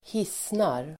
Ladda ner uttalet
Uttal: [²h'is:nar]